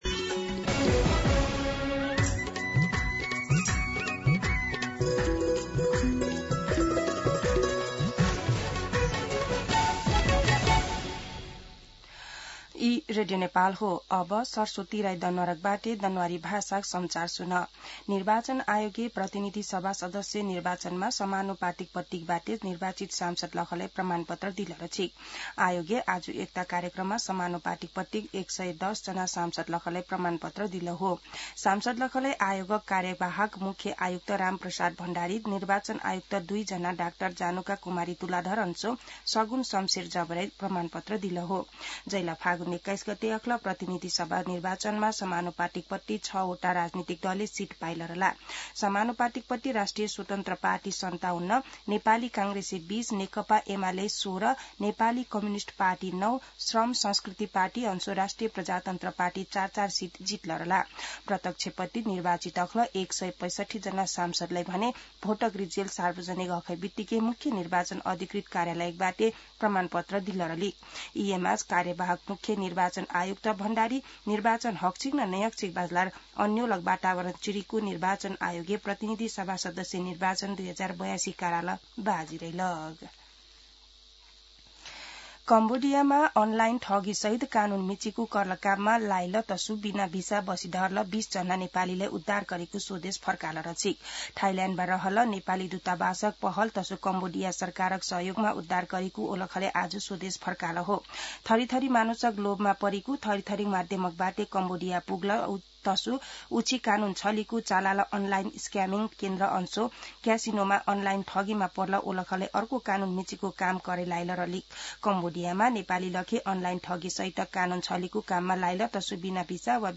दनुवार भाषामा समाचार : ५ चैत , २०८२
Danuwar-News-12-5.mp3